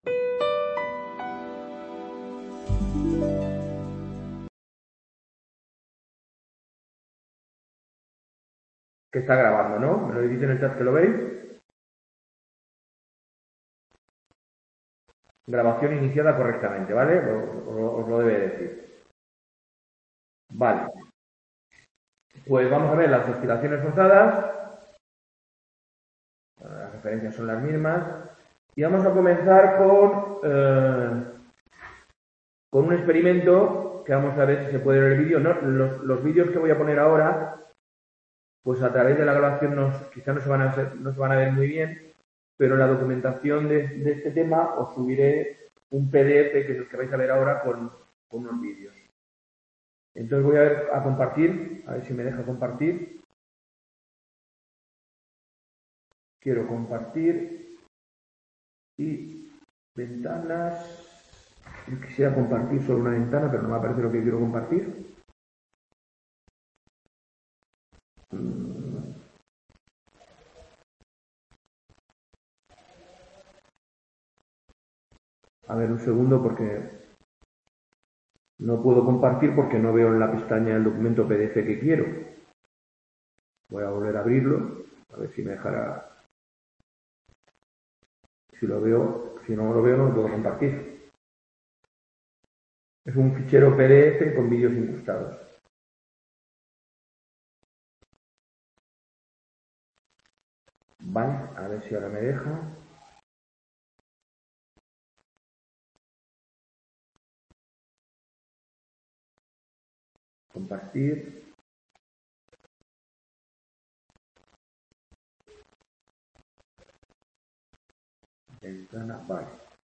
Tema I Tutoría Virtual.